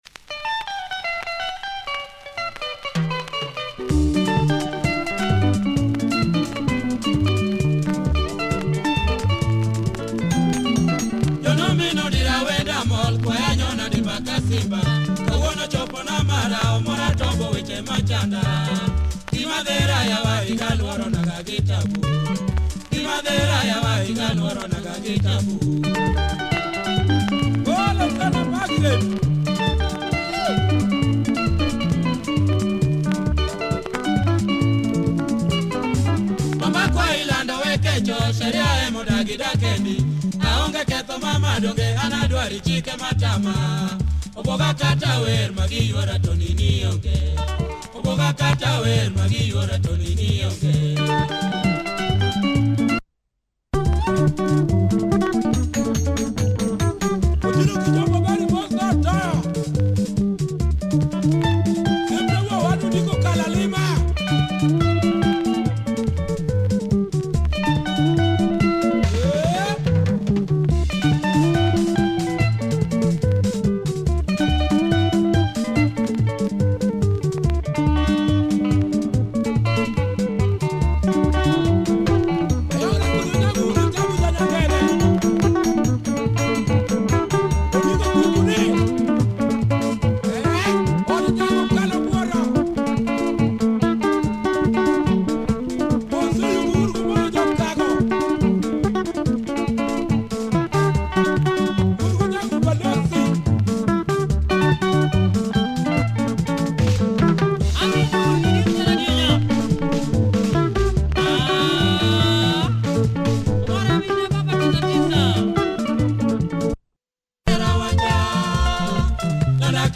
Quality Luo benga